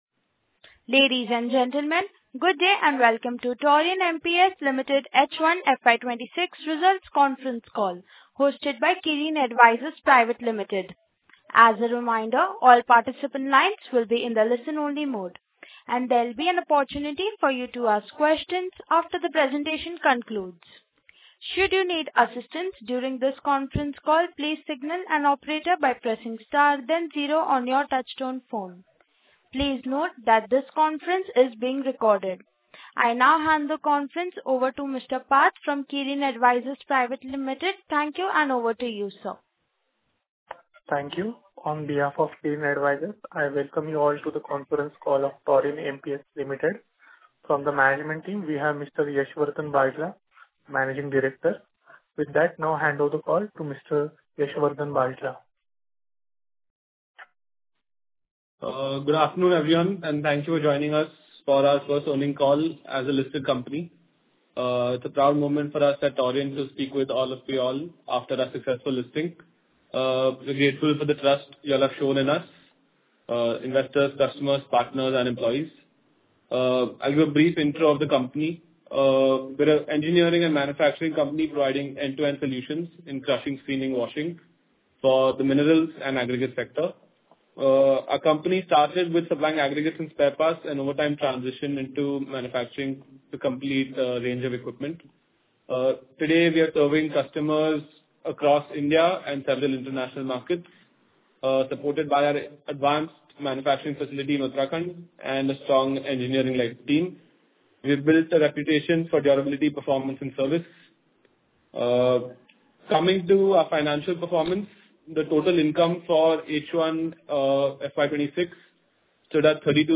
Taurian-MPS-Limited-H1-Concall-Recording.mp3